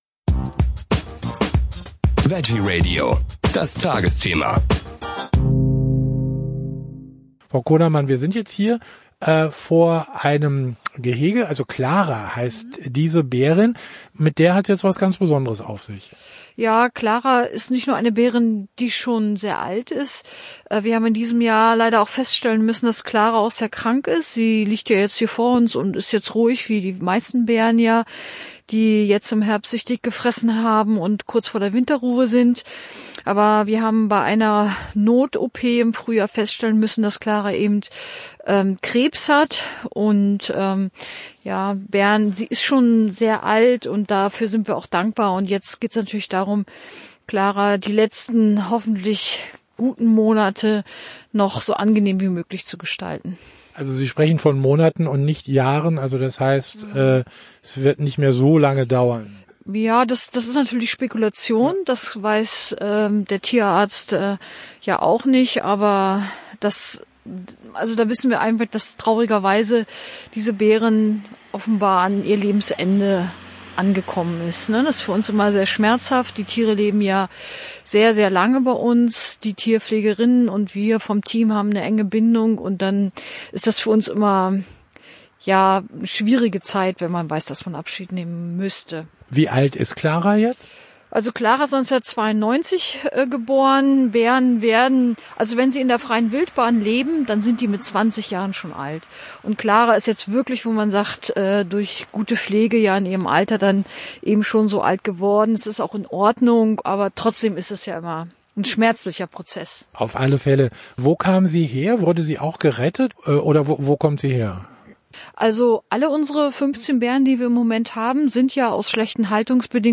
Und-Nein, die Bären sind nicht im Winterschlaf. Wir haben einen Rundgang durch den Bärenwald Müritz gemacht.